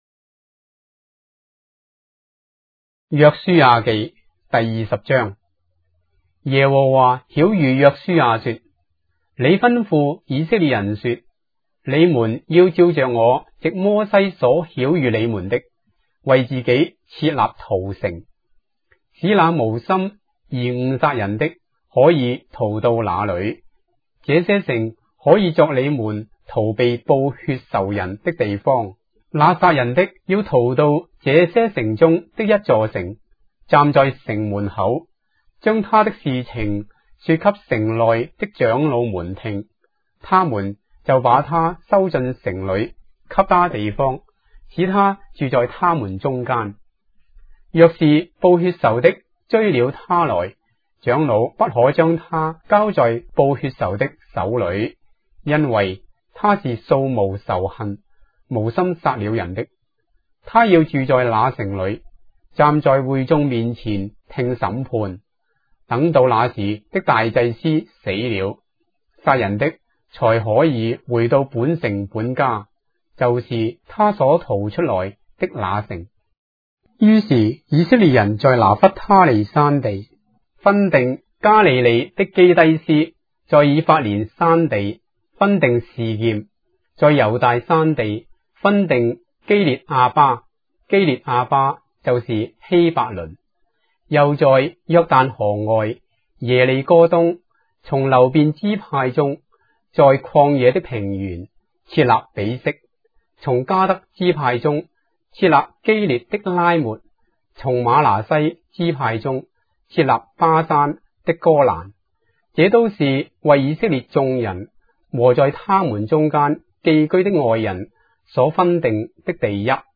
章的聖經在中國的語言，音頻旁白- Joshua, chapter 20 of the Holy Bible in Traditional Chinese